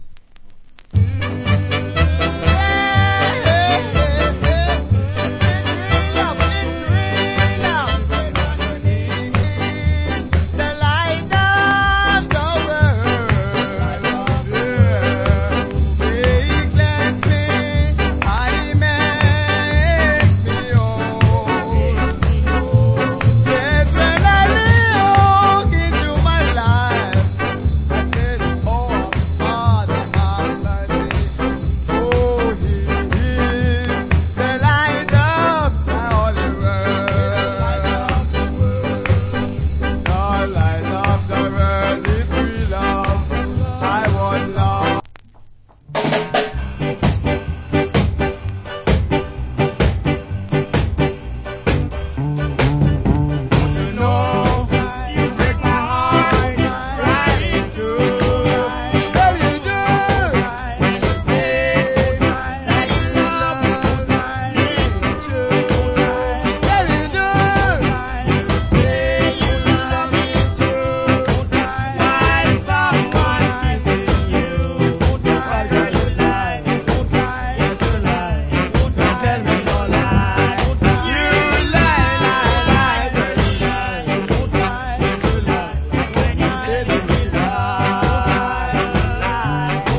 Nice Ska.